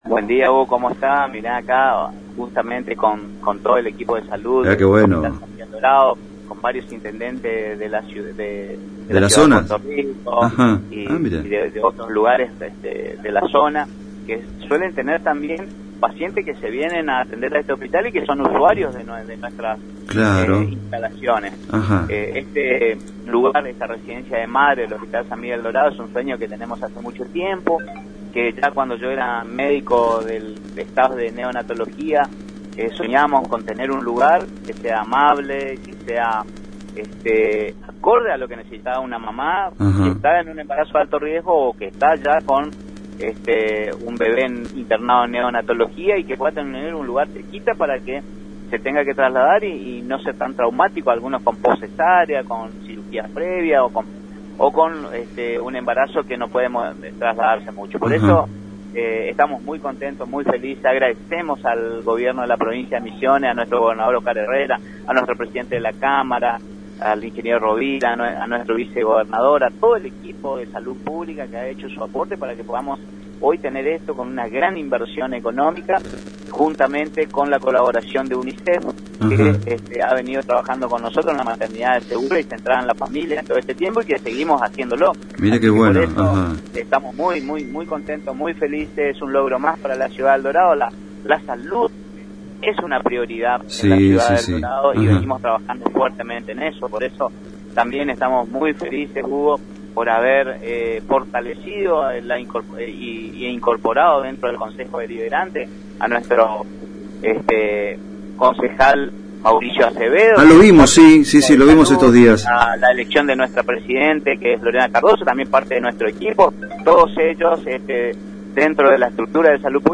Es un logro mas para la ciudad de Eldorado, la prioridad es la salud”manifestó el ministro de Salud Publica de Misiones Oscar Alarcon en dialogo con ANG y Multimedos Gènesis.